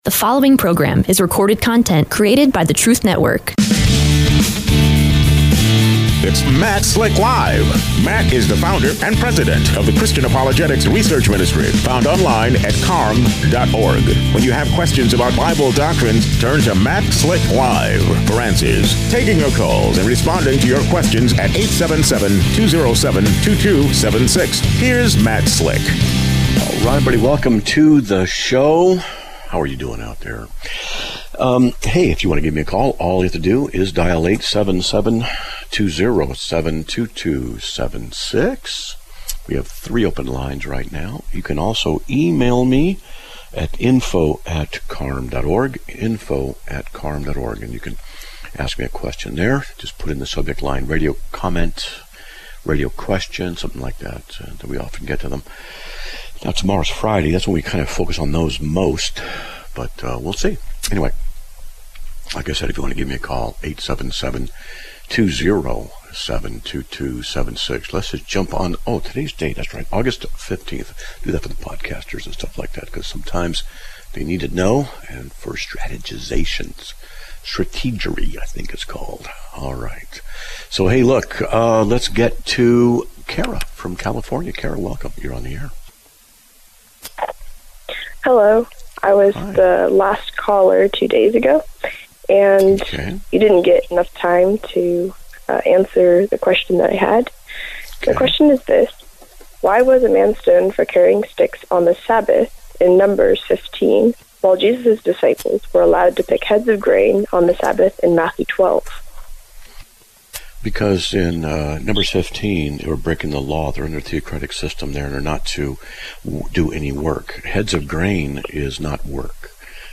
Live Broadcast